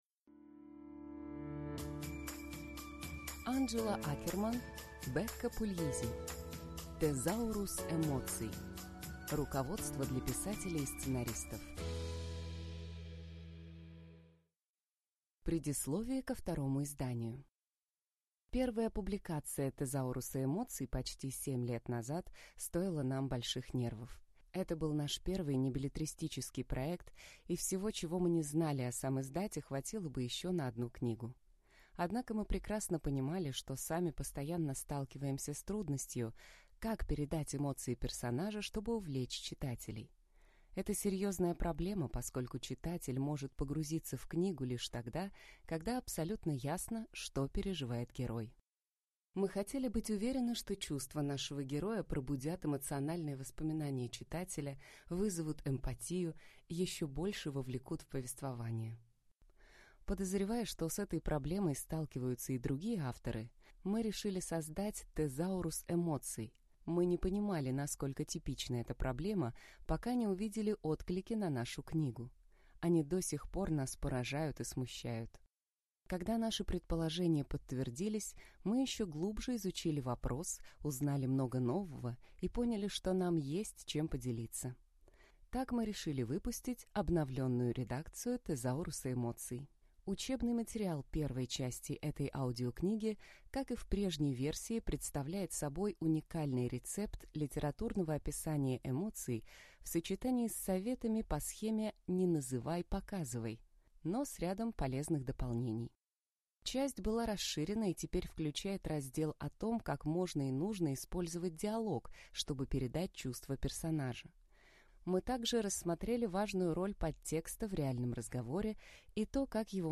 Аудиокнига Тезаурус эмоций. Руководство для писателей и сценаристов | Библиотека аудиокниг